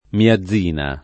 [ mia zz& na ]